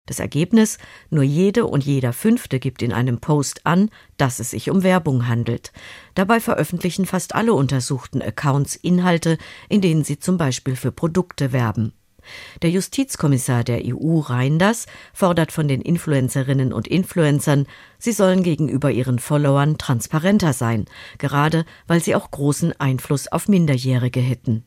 SWR-Reporterin